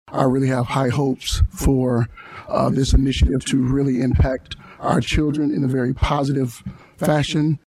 Trustee Jermaine Jackson says he is in full support of the extra classroom time.